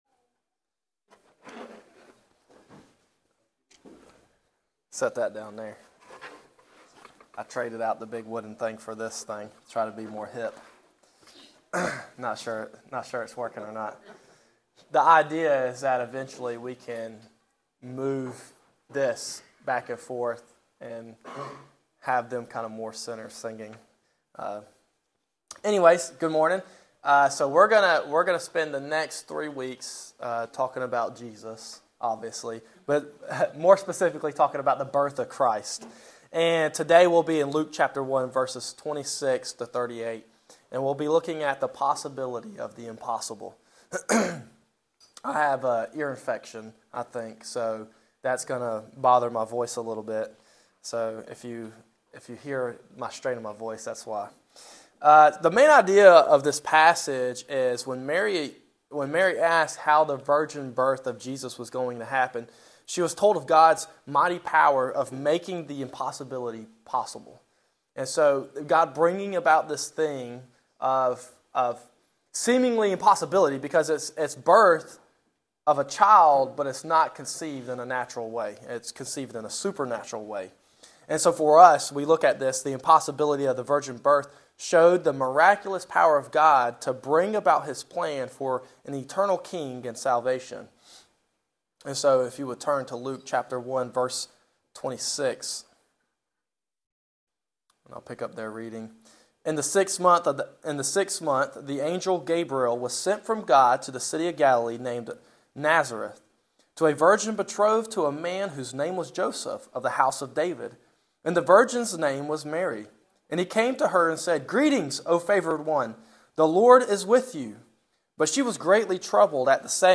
Sermon Audio
Christmas Sermons